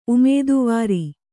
♪ umēduvāri